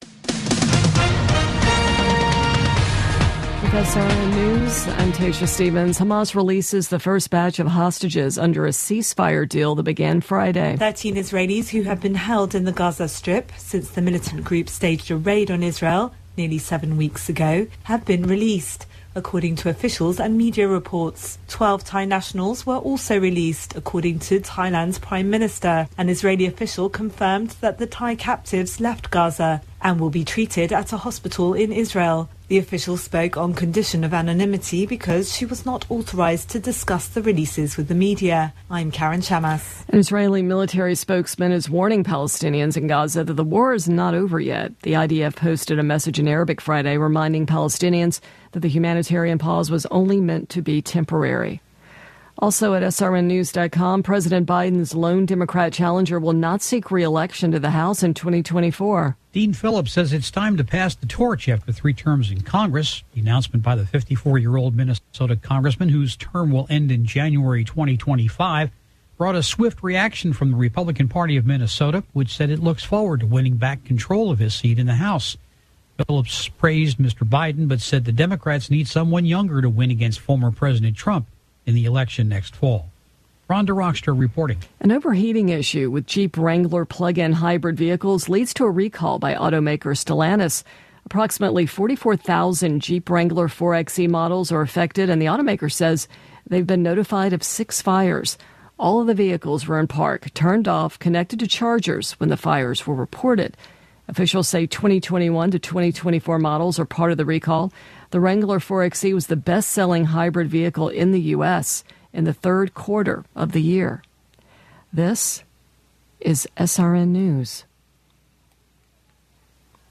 Latest news stories from around the world brought to you at the top of the hour